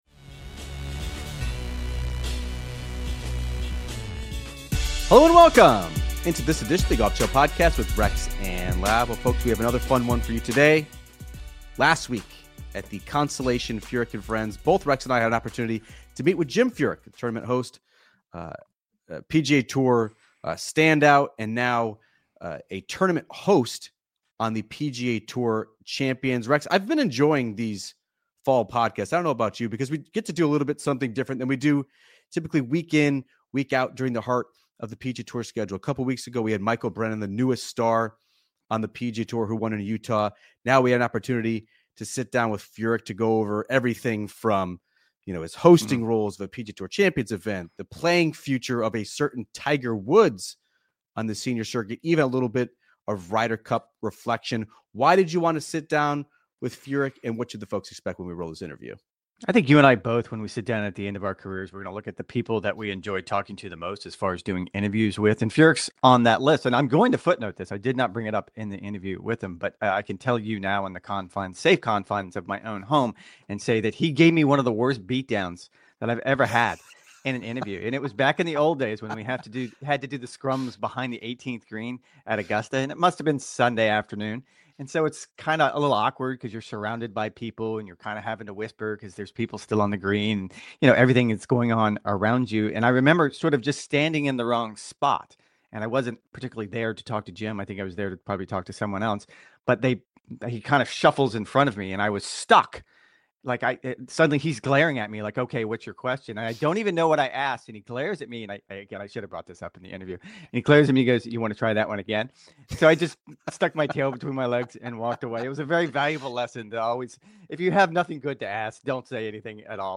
0:00: Interview SZN continues on the pod